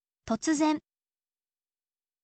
totsuzen